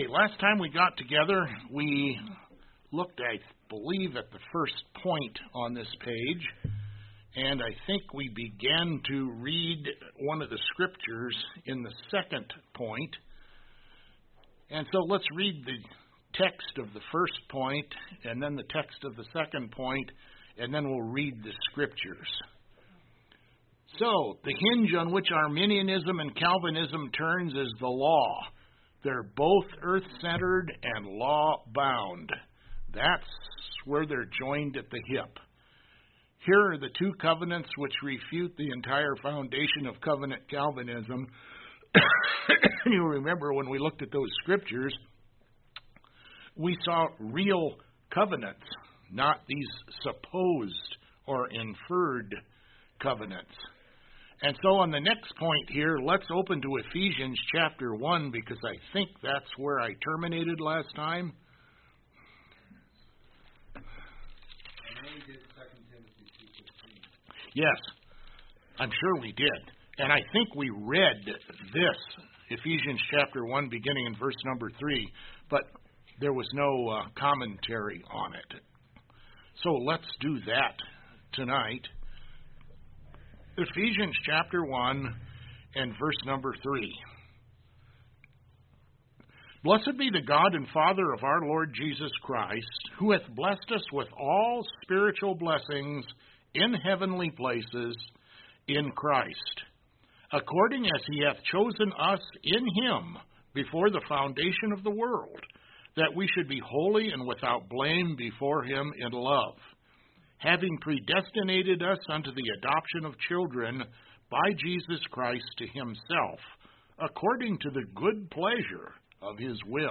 A Non-Denominational Bible Church in Black Forest, Colorado
Preacher: Black Forest Bible Church